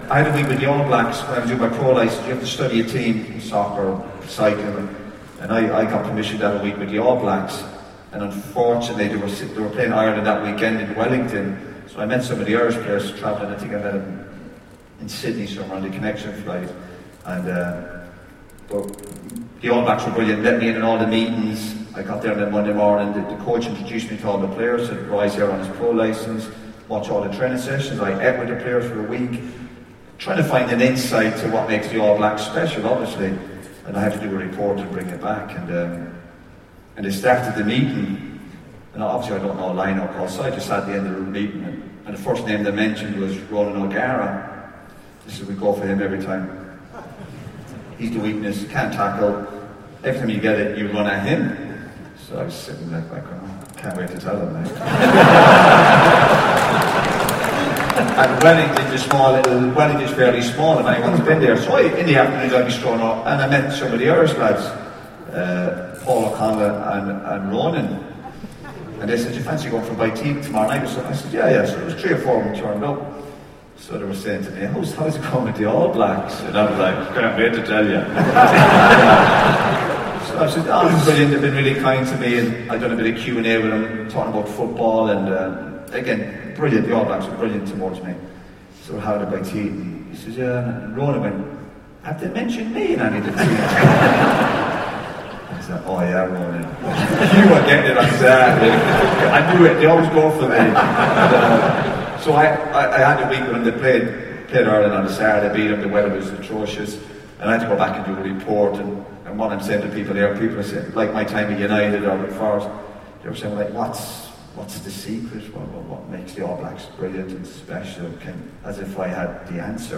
Speaking at an Easons event to promote his new book 'The Second Half', Roy Keane recalls a week spent shadowing the All Blacks as part of his UEFA Pro License.